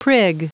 Prononciation du mot prig en anglais (fichier audio)
Prononciation du mot : prig